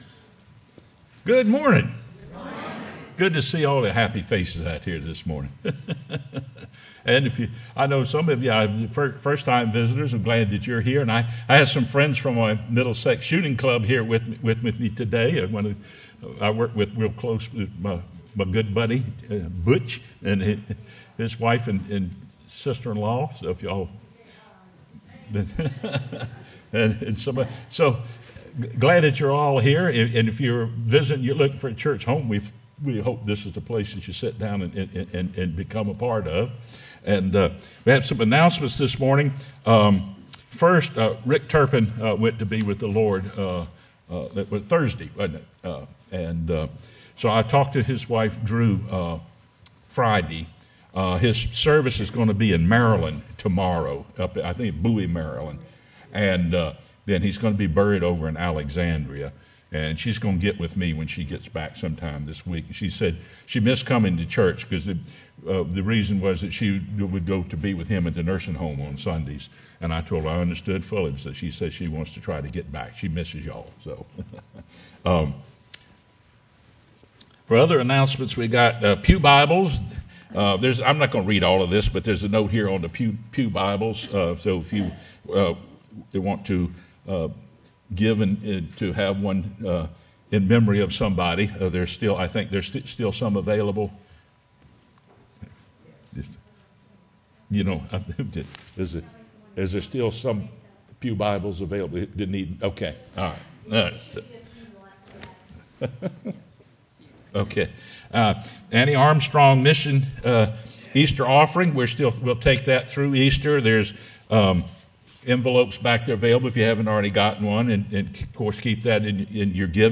sermonMar30-CD.mp3